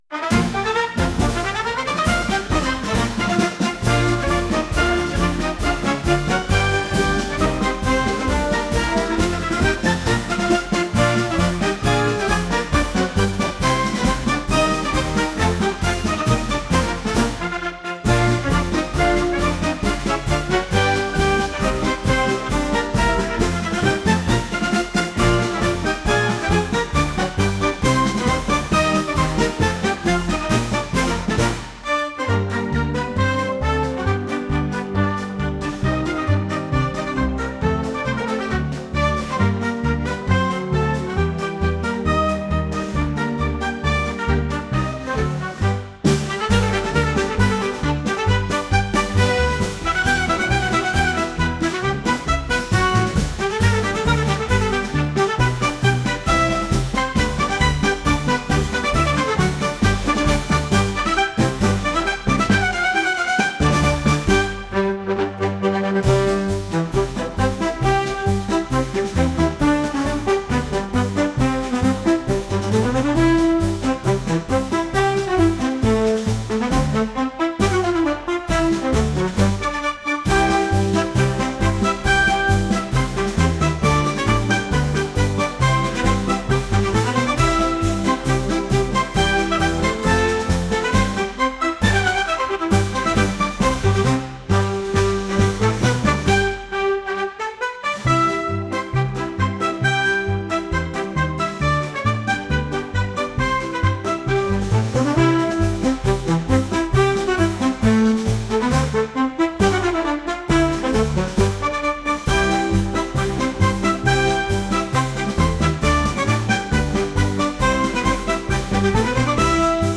棒銀で突き進むようなマーチング曲です。